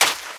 STEPS Sand, Run 22.wav